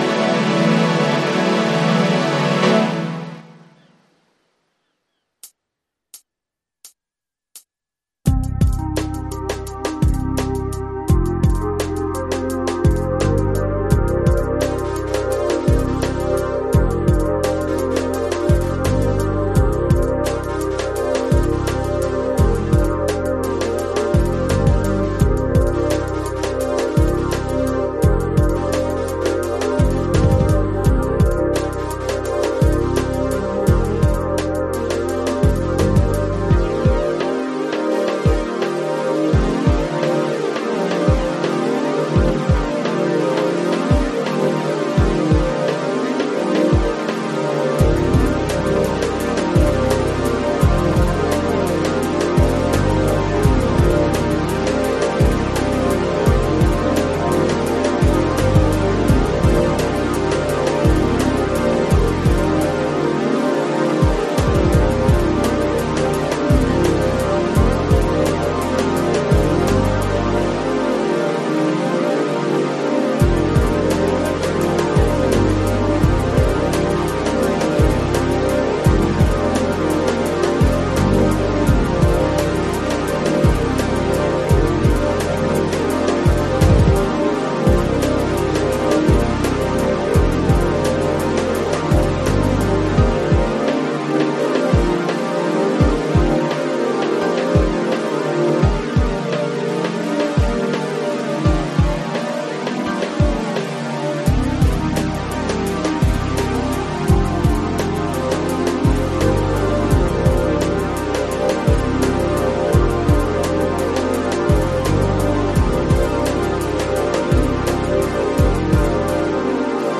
versão instrumental multipista